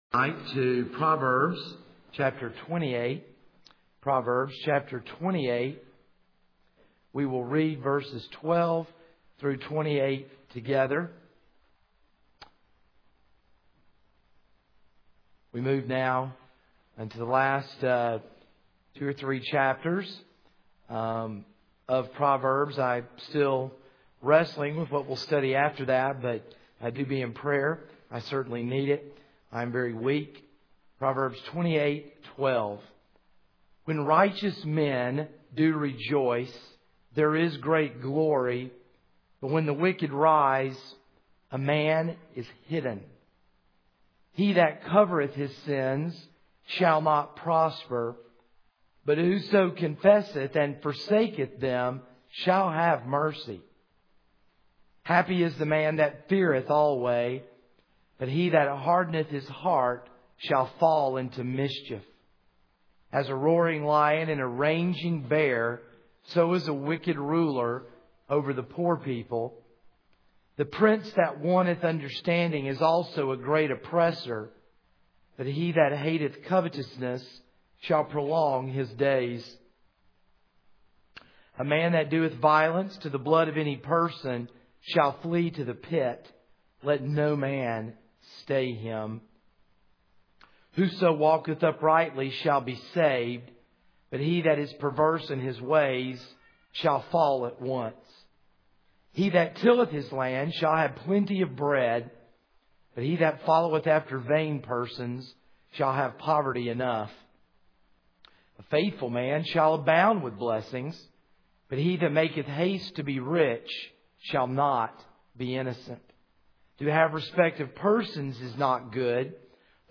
This is a sermon on Proverbs 28:12-28 (Part 1 of 2).